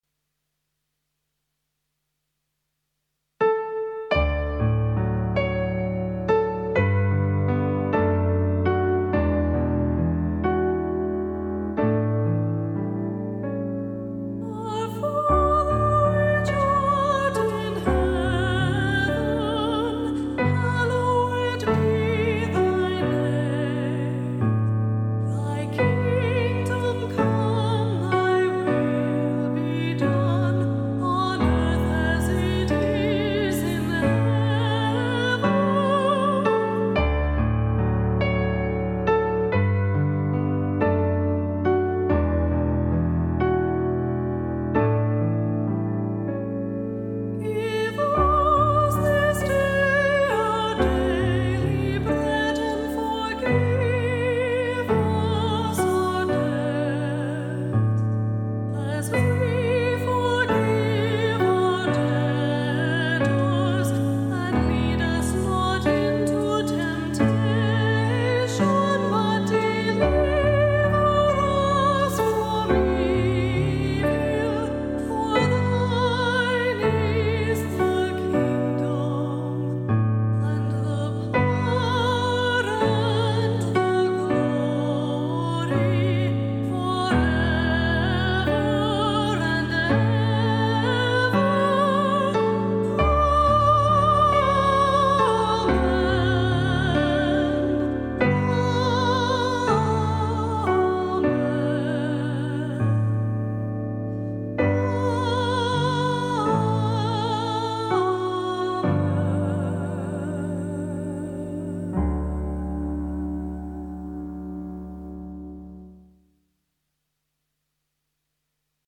Solo / Unison voice with Keyboard accompaniment
voice with piano accompaniment.
The demonstration version is sung by
Mezzo-soprano